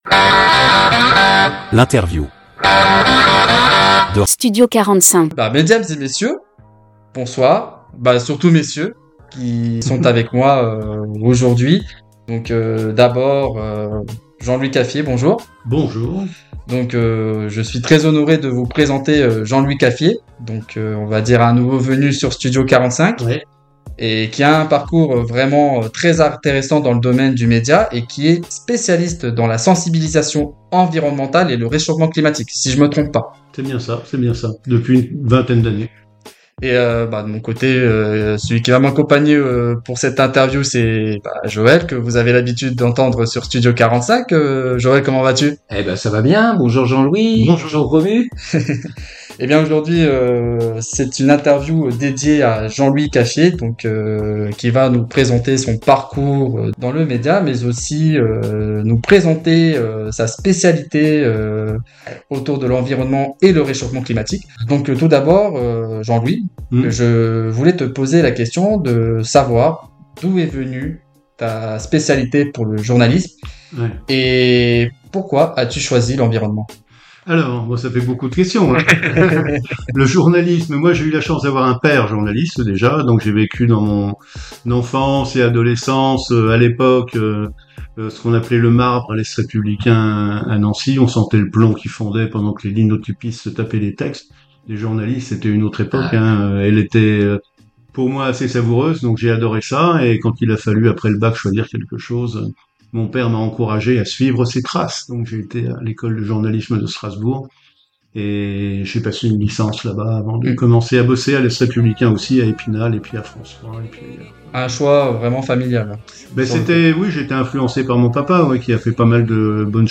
Interview de Studio 45 - Jean-Louis Caffier